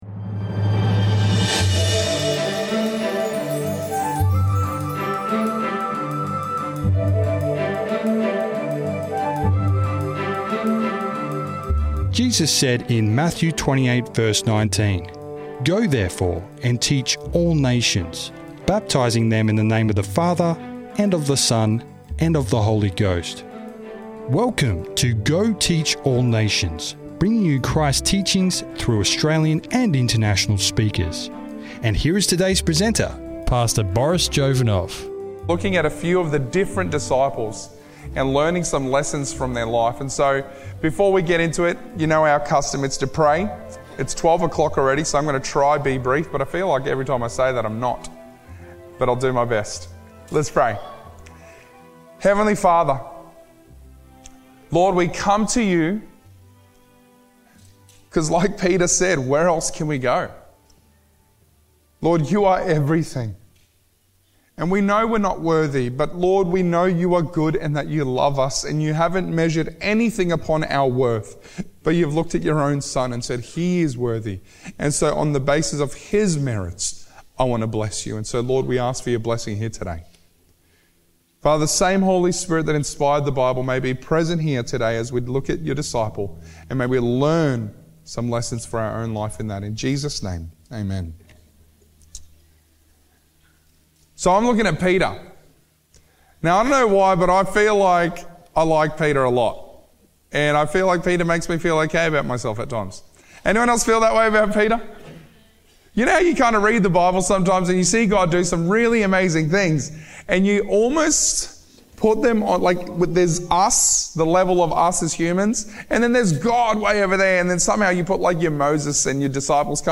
This message was made available by the Murwillumbah Seventh-day Adventist church.